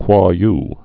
(kwôy)